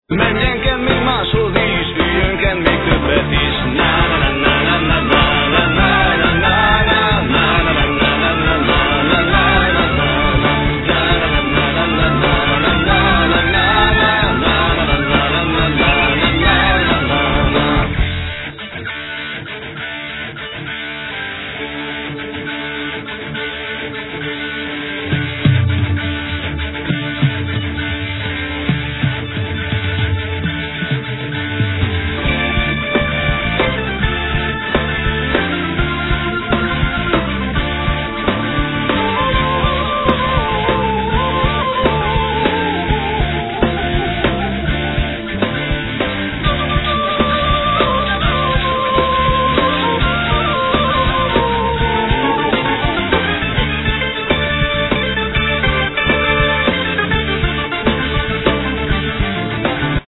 Vocals, Bass
Flute, Turk-pipe